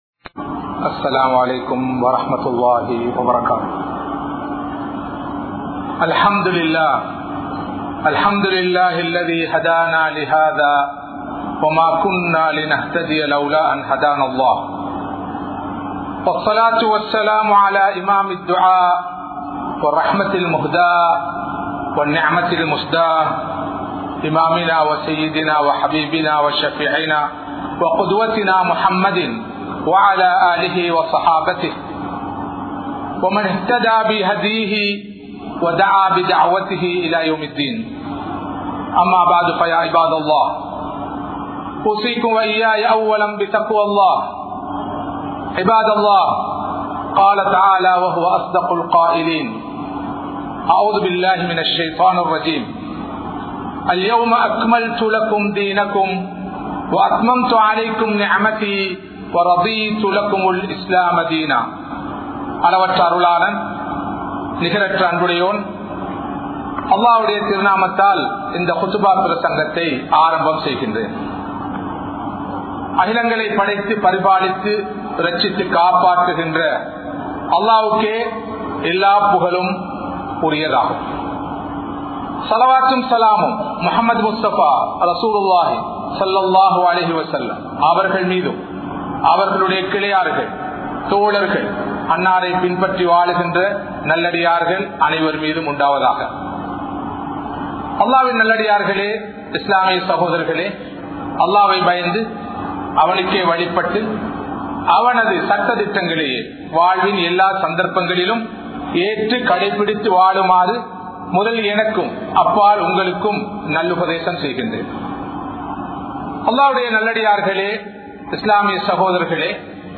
Atputhamaana Manithar Nabi(SAW)Avarhal (அற்புதமான மனிதர் நபி(ஸல்)அவர்கள்) | Audio Bayans | All Ceylon Muslim Youth Community | Addalaichenai
Kollupitty Jumua Masjith